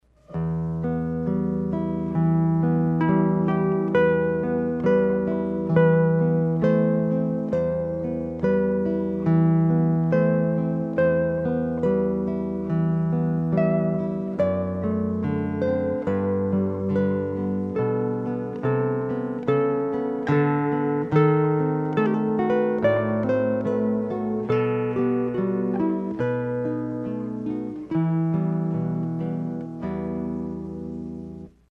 A seguir são disponibilizados alguns pequenos arquivos MP3 demonstrando a sonoridade original e a sonoridade após a equalização.
Violão sem Caixa de Ressonância
Música (sem eq.)
Obs: A duas primeiras amostras do violão sem caixa de ressonância foram obtidas com um Violão Miranda Modelo CFX-200.